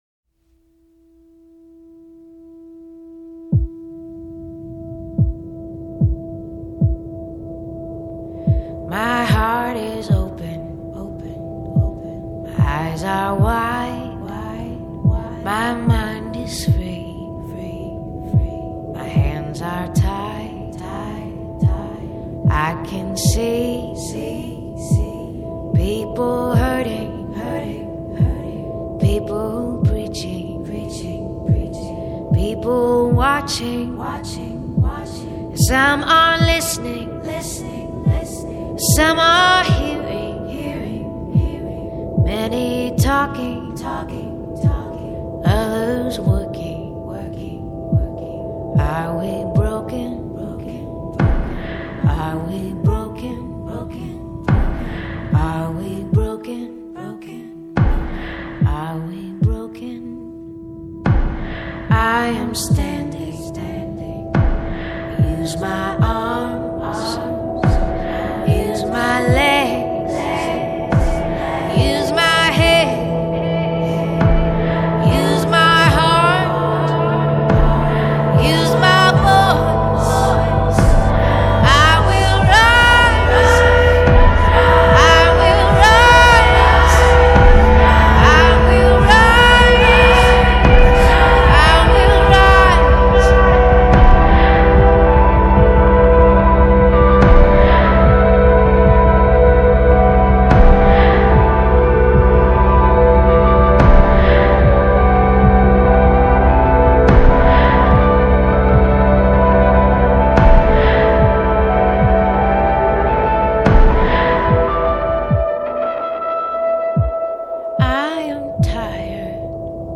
Jazz, Pop, Folk